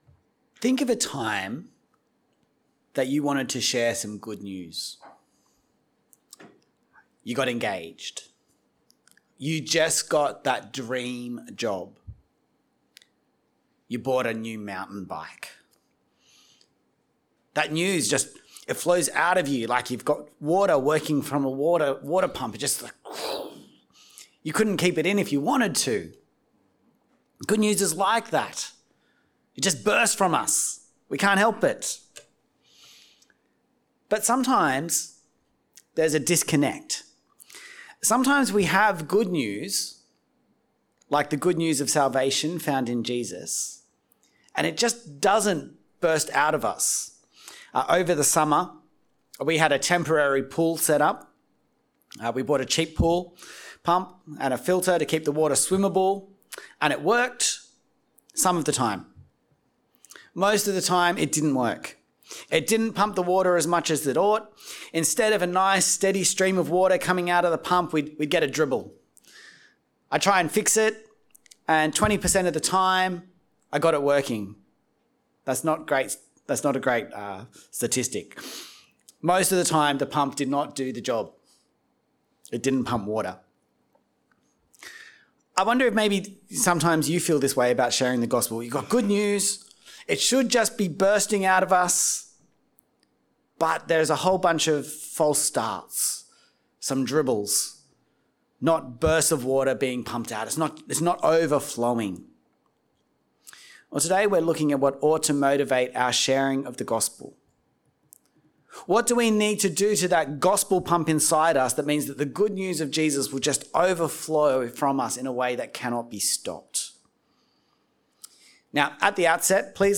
Message: "Jesus' Glory"